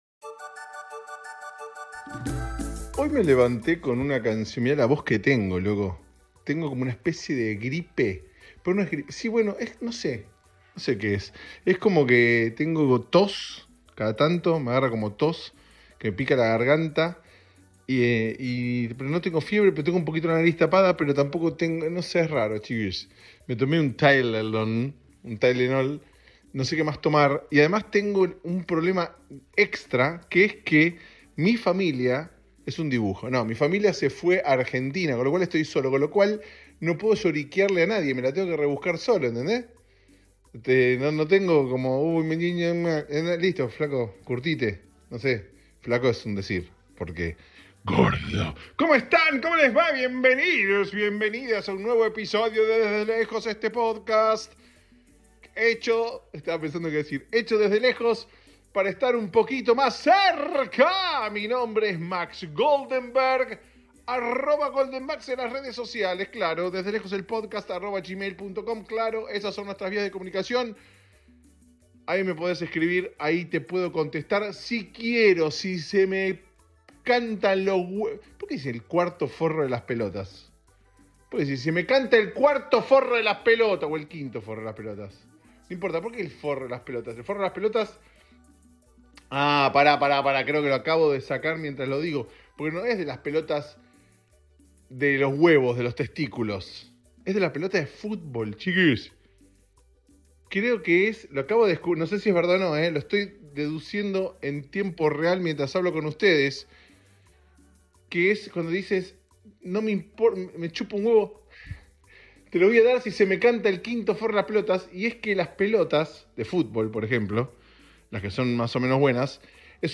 Solo pero firme frente al micrófono de este podcast.